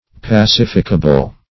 pacificable \pa*cif"ic*a*ble\, a.
pacificable.mp3